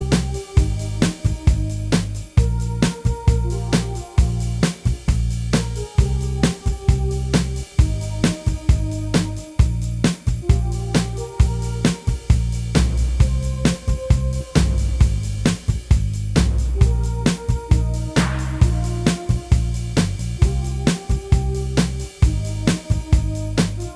Minus Main Guitar Pop (2010s) 2:53 Buy £1.50